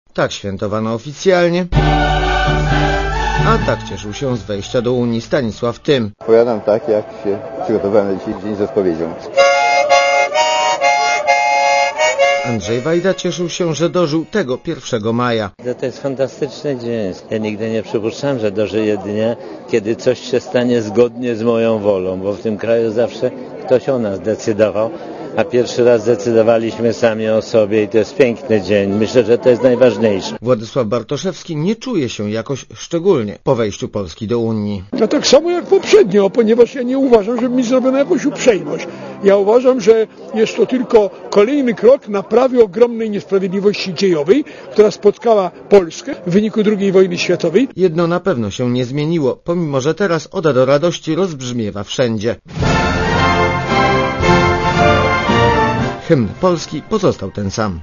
Źródło: PAP Relacja reportera Radia ZET Oceń jakość naszego artykułu: Twoja opinia pozwala nam tworzyć lepsze treści.